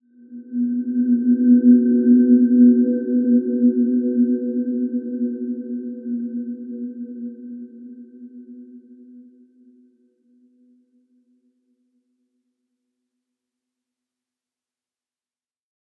Dreamy-Fifths-B3-f.wav